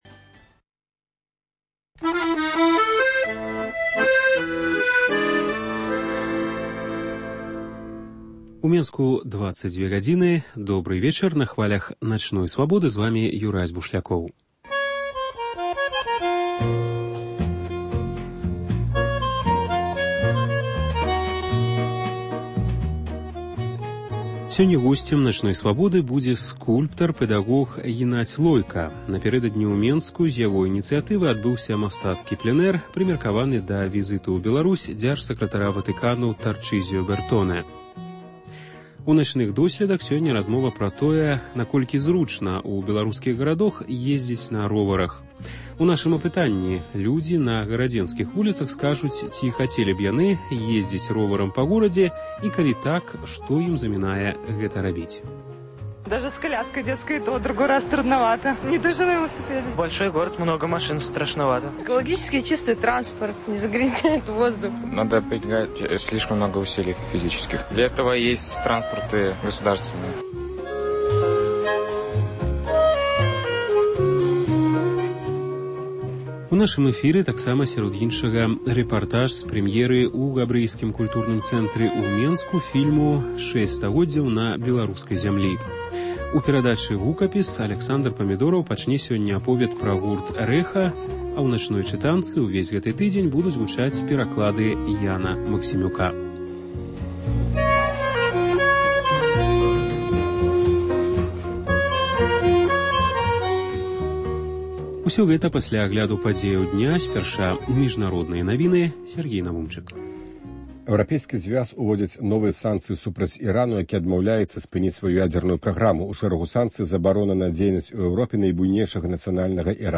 * “Начныя досьледы”: размова пра тое, наколькі зручна ў беларускіх гарадох езьдзіць на роварах? У нашым апытаньні людзі на гарадзенскіх вуліцах скажуць, ці хацелі б яны езьдзіць роварам па горадзе і, калі так, што ім замінае гэта рабіць.